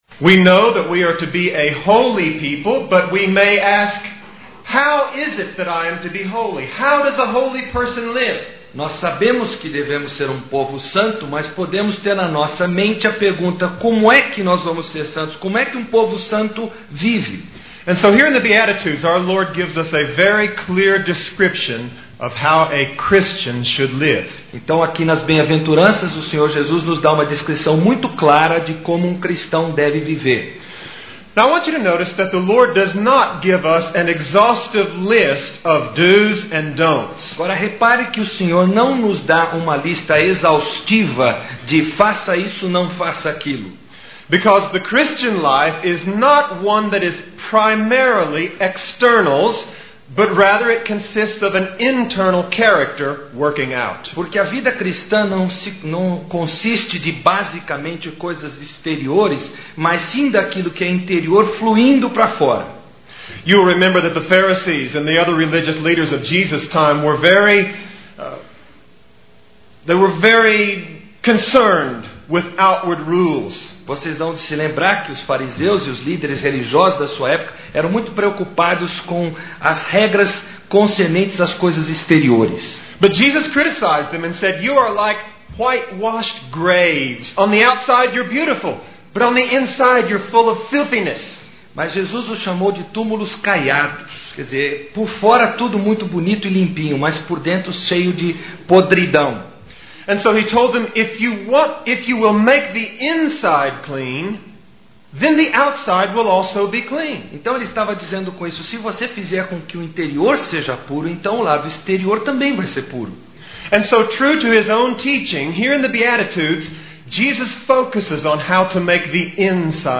17ª Conferência Fiel para Pastores e Líderes – Brasil - Ministério Fiel